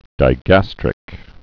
(dī-găstrĭk) Anatomy